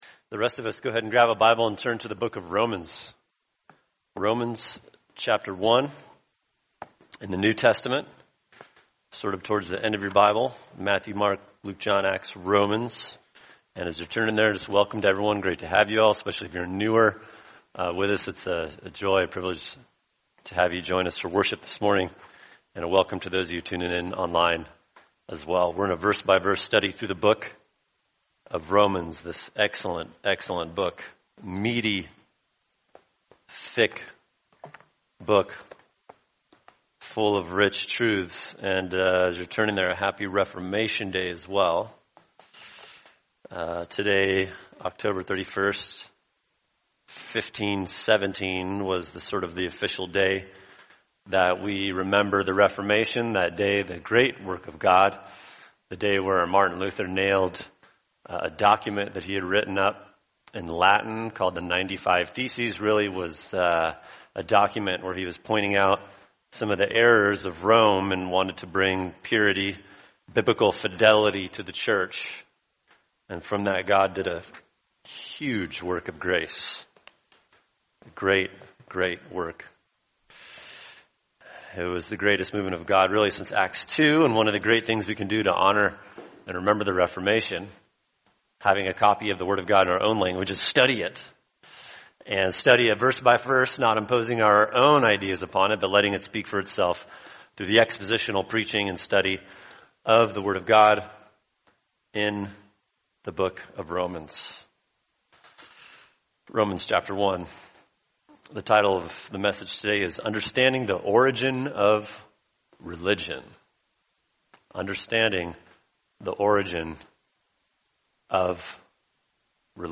[sermon] Romans 1:23 Understanding the Origin of Religion | Cornerstone Church - Jackson Hole